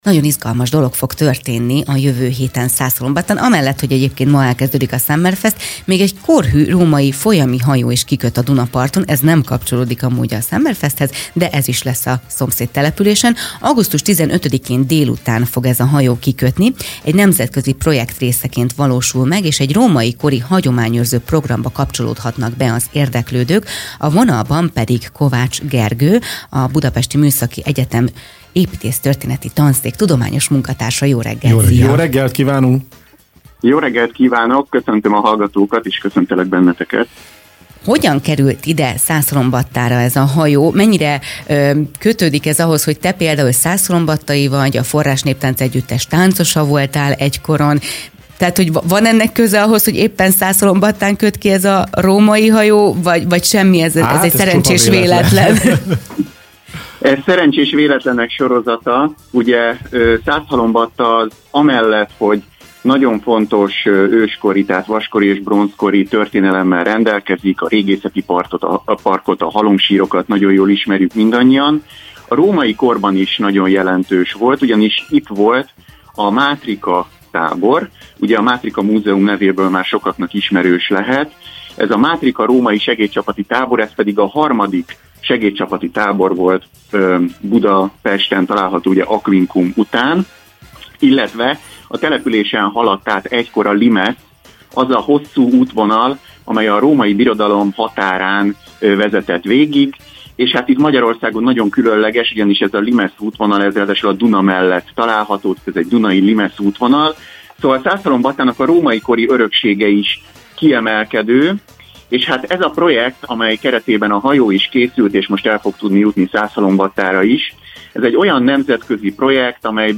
beszélgettünk a Bundáskenyérben